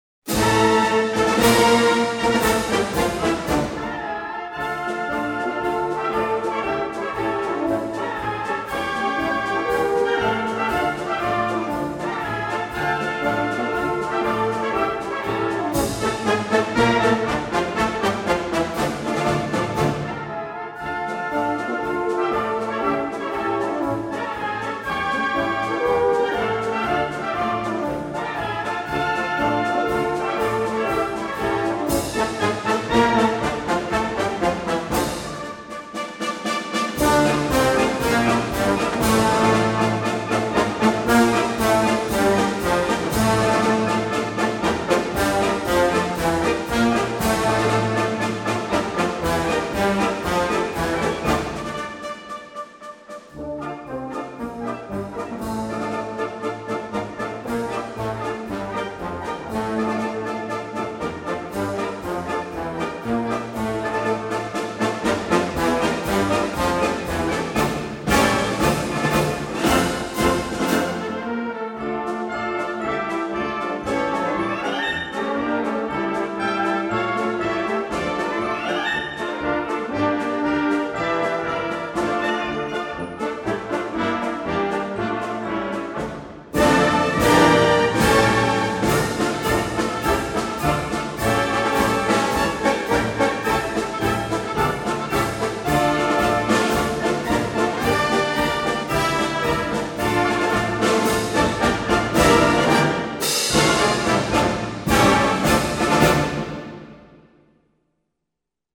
Описание: Качество отличное